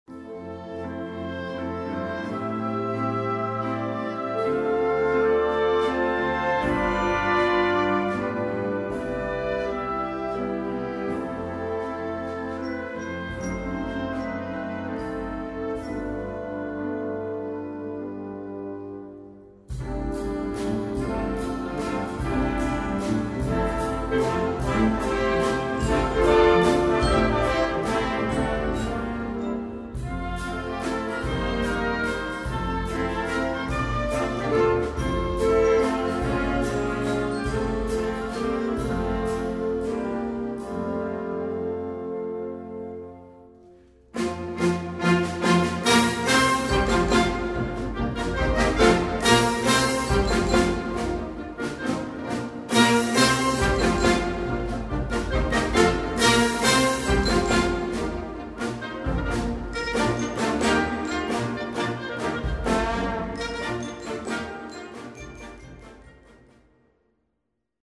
Répertoire pour Orchestre